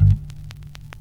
Bass_Stab_01.wav